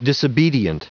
Prononciation du mot disobedient en anglais (fichier audio)
Prononciation du mot : disobedient